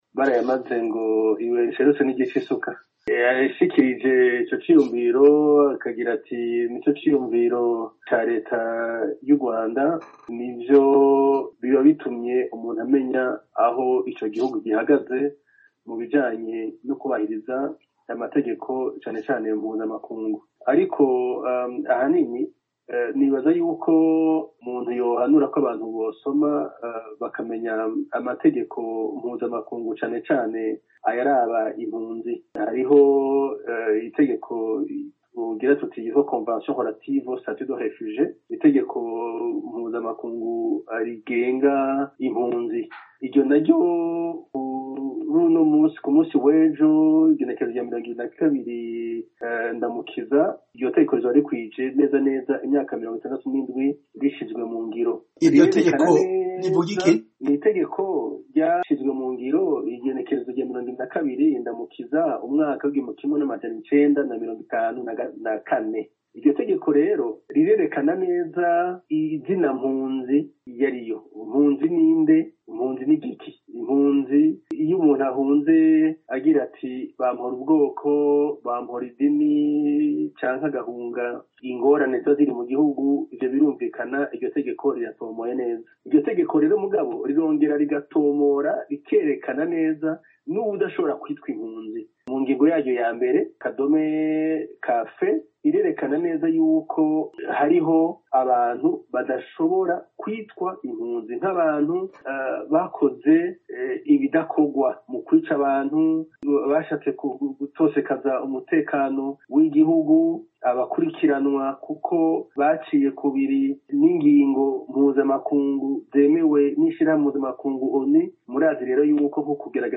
ikiganiro